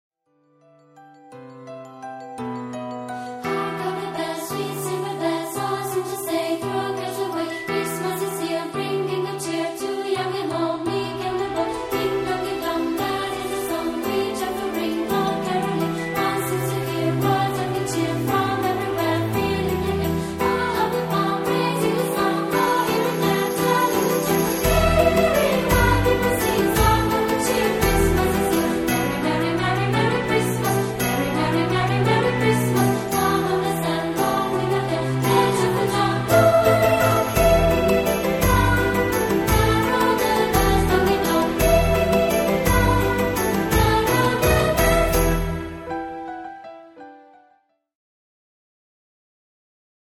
sop 1